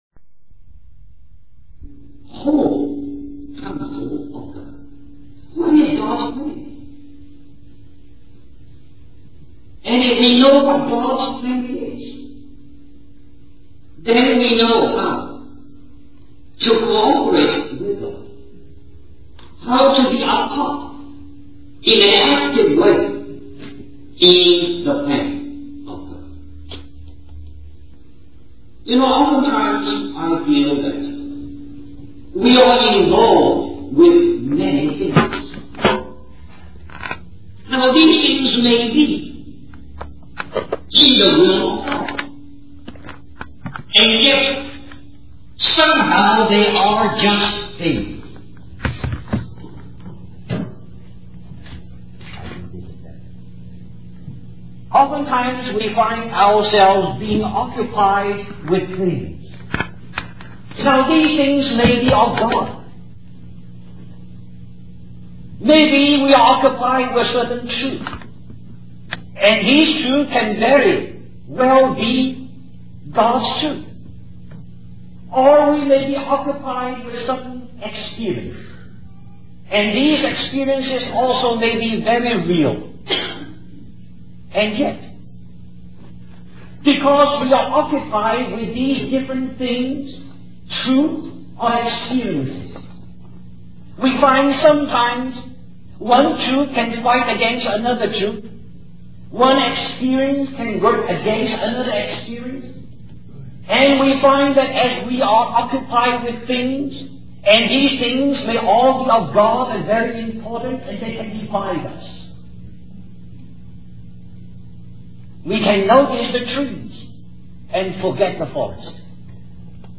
Christian Family Conference We apologize for the poor quality audio